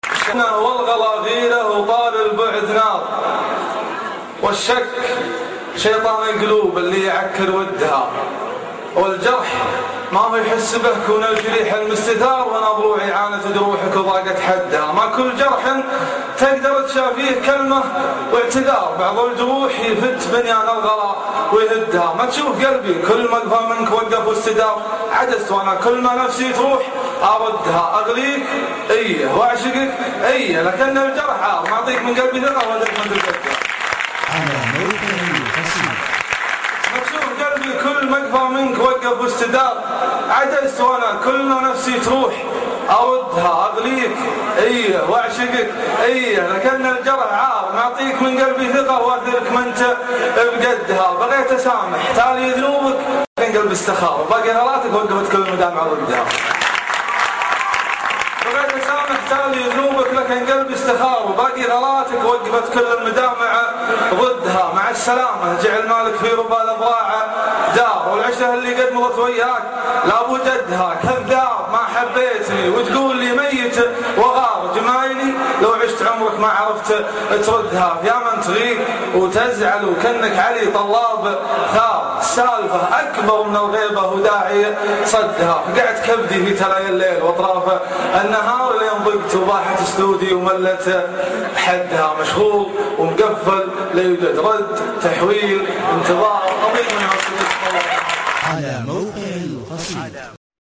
طاري البعد نار ( اصبوحة جامعة الكويت